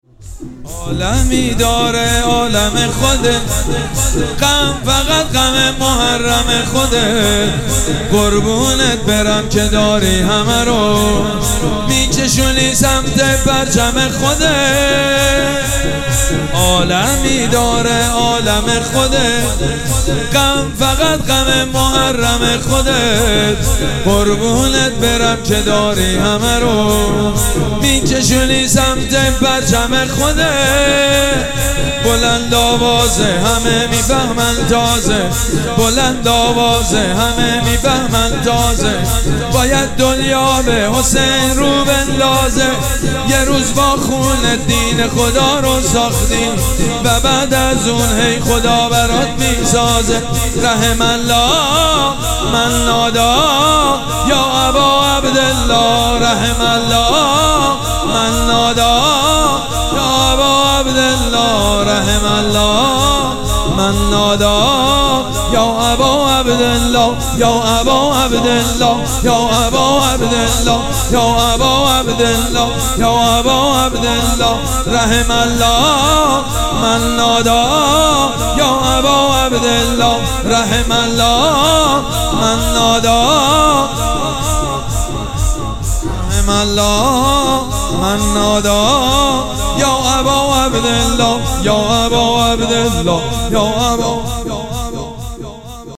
شب پنجم مراسم عزاداری اربعین حسینی ۱۴۴۷
شور
مداح
حاج سید مجید بنی فاطمه